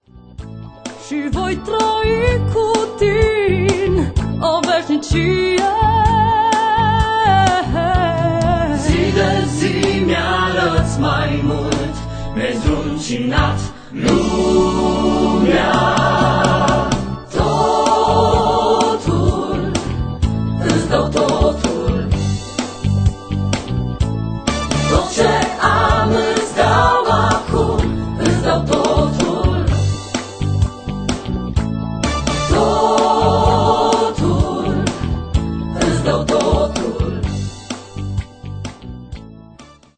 Este un adevarat compendiu de lauda si inchinare.